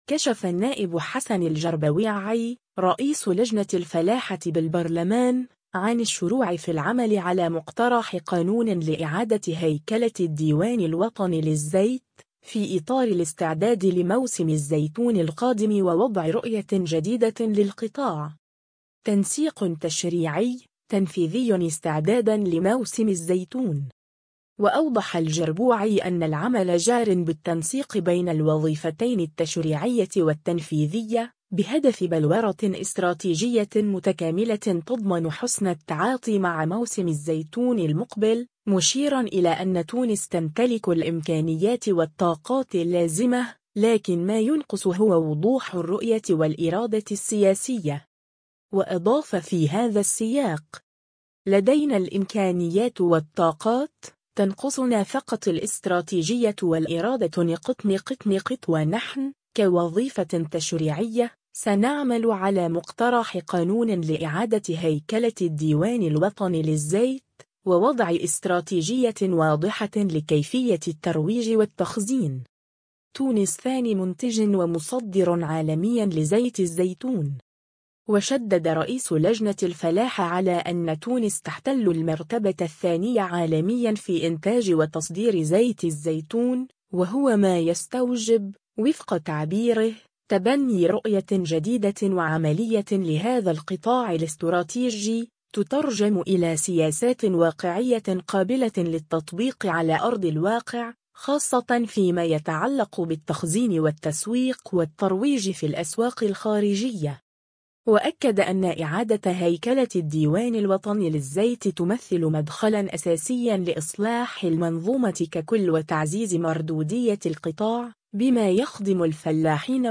رئيس لجنة الفلاحة بالبرلمان لـ”الرقمية” : مقترح قانون لإعادة هيكلة الديوان الوطني للزيت (فيديو)